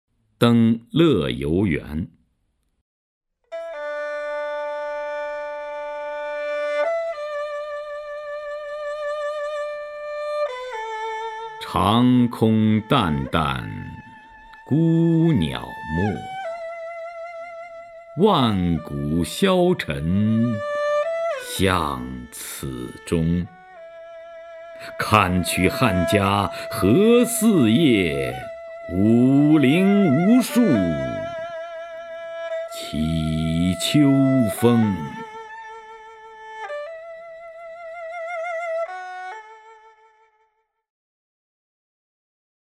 徐涛朗诵：《登乐游原》(（唐）杜牧) （唐）杜牧 名家朗诵欣赏徐涛 语文PLUS